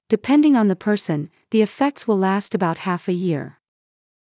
ﾃﾞｨﾍﾟﾝﾃﾞｨﾝｸﾞ ｵﾝ ｻﾞ ﾊﾟｰｿﾝ ｼﾞ ｴﾌｪｸﾂ ｳｨﾙ ﾗｽﾄ ｱﾊﾞｳﾄ ﾊｰﾌ ｱ ｲﾔｰ